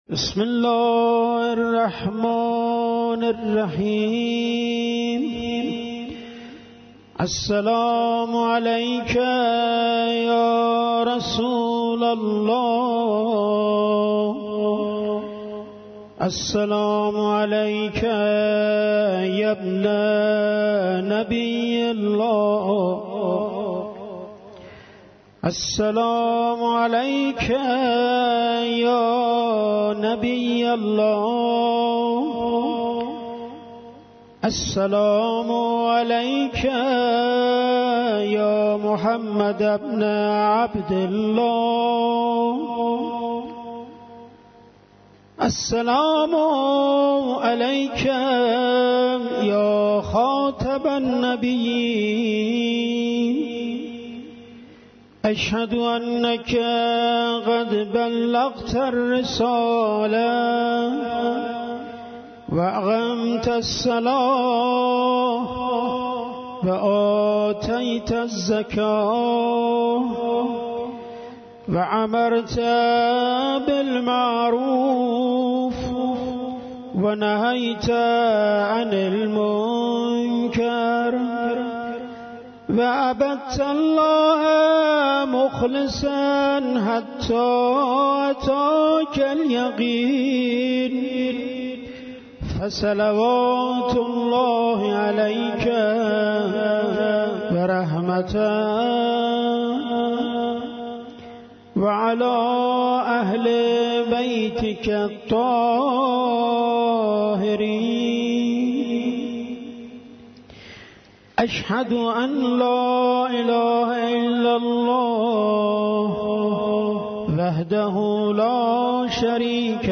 قرائت ادعیه